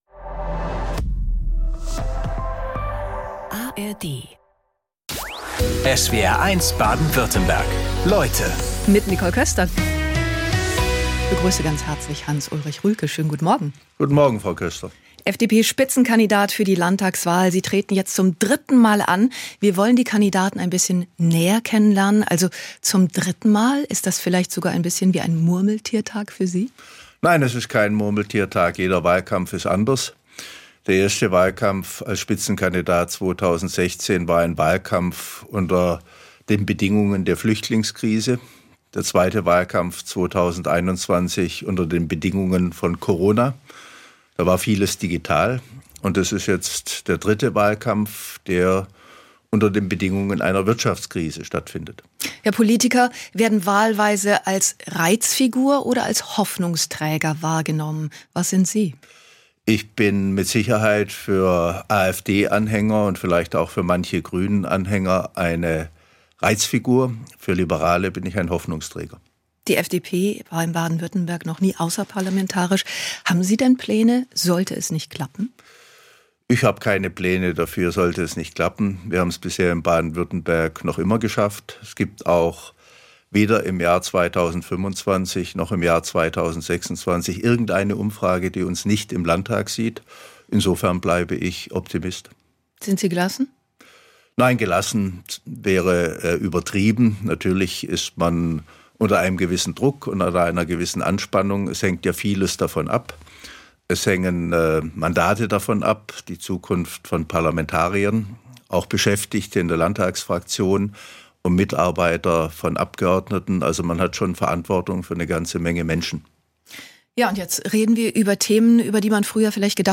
Beschreibung vor 1 Monat Vor der Landtagswahl 2026 sind Spitzenpolitiker:innen aus Baden-Württemberg zu Gast in SWR1 Leute, unter anderem Dr. Hans-Ulrich Rülke von der FDP. Wir haben mit ihm über die Themen Wirtschaft, Migration, Bildung, Mobilität & Verkehr, Umwelt- und Klimaschutz gesprochen. Den Inhalt der Sendung haben wir einer Faktenüberprüfung unterzogen und die Ergebnisse in die Podcast-Folge eingebaut.